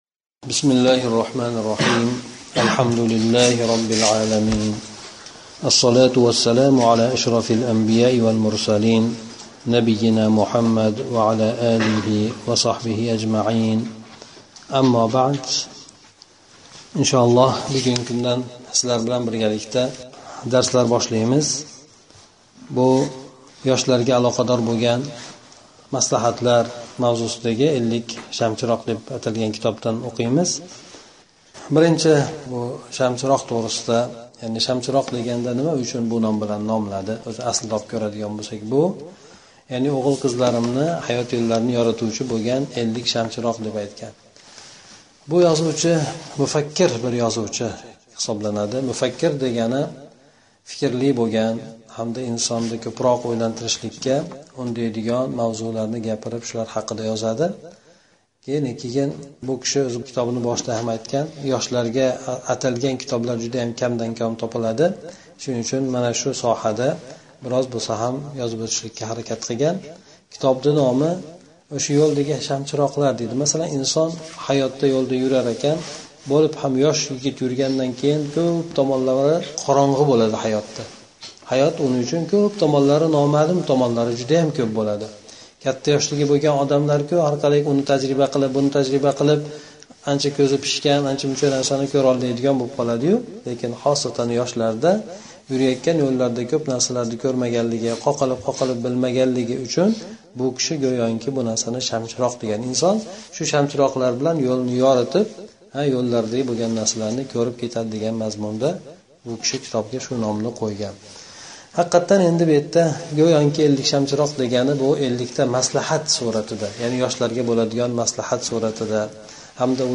Ушбу маърузаларда китоб қисқача шарҳ қилинган.